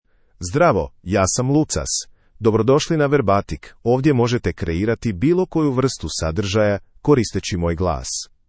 MaleCroatian (Croatia)
LucasMale Croatian AI voice
Lucas is a male AI voice for Croatian (Croatia).
Voice sample
Listen to Lucas's male Croatian voice.
Lucas delivers clear pronunciation with authentic Croatia Croatian intonation, making your content sound professionally produced.